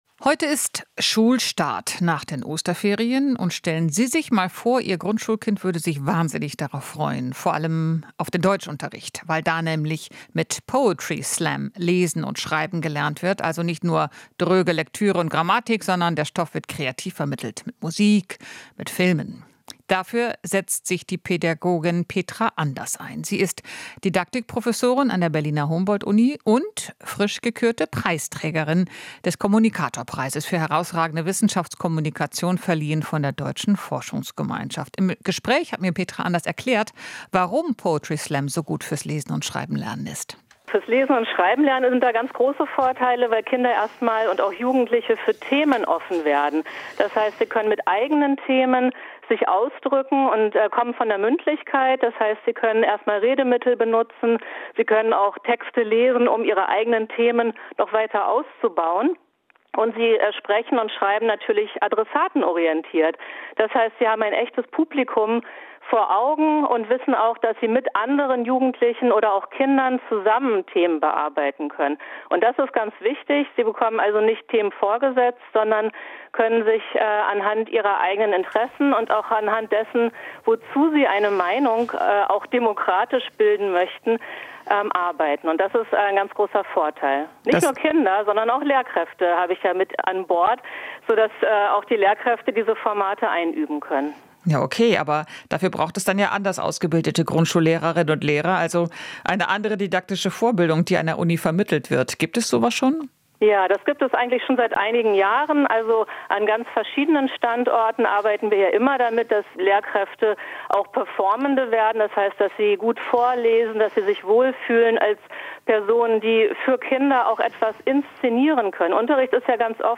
Interview - Pädagogin: Kinder sollten anhand eigener Interessen lernen